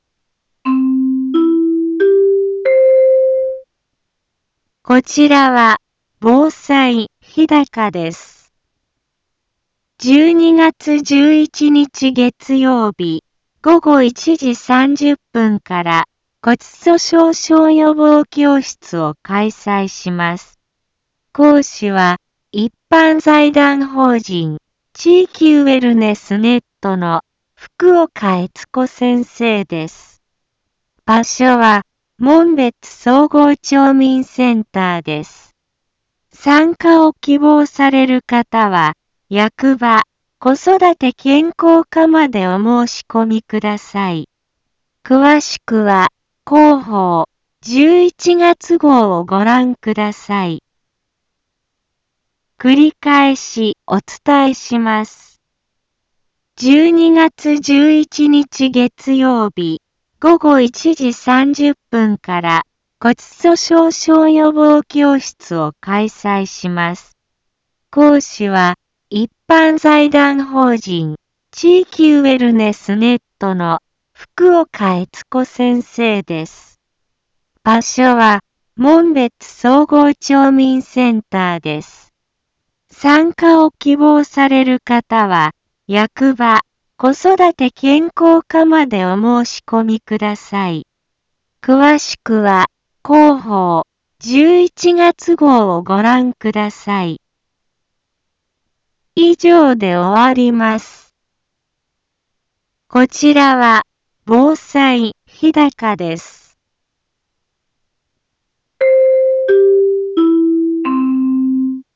一般放送情報
Back Home 一般放送情報 音声放送 再生 一般放送情報 登録日時：2023-12-04 15:03:55 タイトル：骨粗しょう症予防教室のお知らせ インフォメーション： 12月11日月曜日、午後1時30分から骨粗しょう症予防教室を開催します。